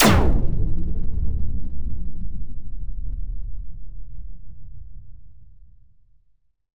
Power Laser Guns Demo
Plasm_gun41.wav